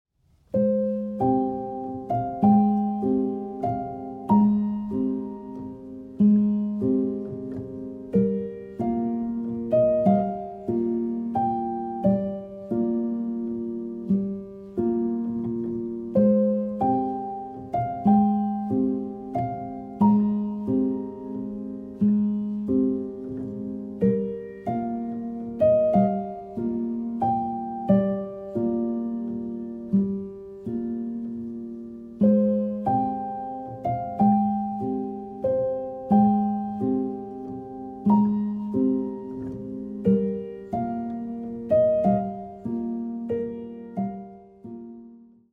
延々と続く桜並木を歩む夢を見るようなアルバムです。